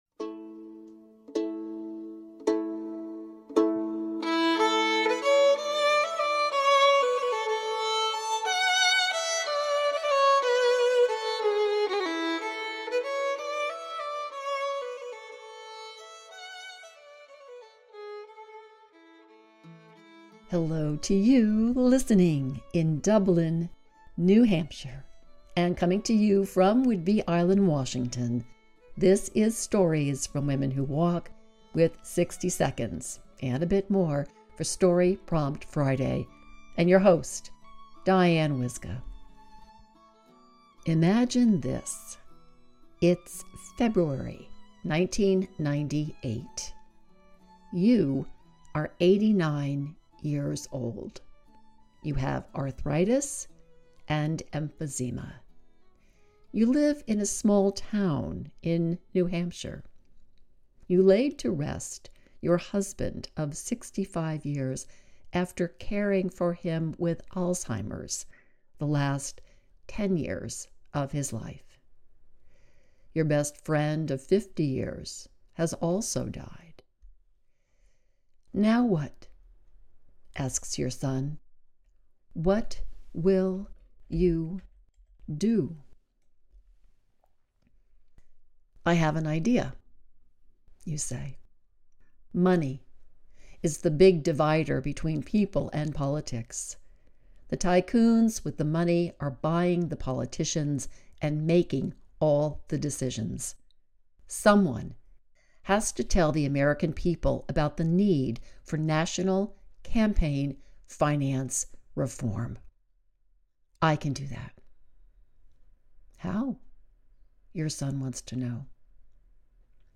Hello to you listening in Queens, NYC, New York!